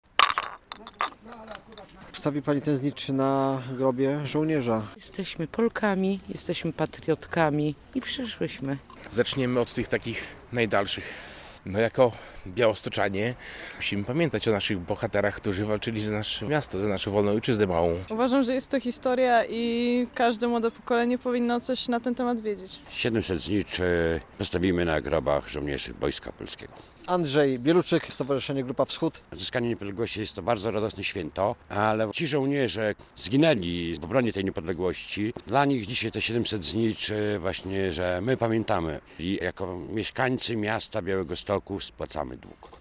Światło Pamięci Niezwyciężonym na cmentarzu wojskowym w Białymstoku - relacja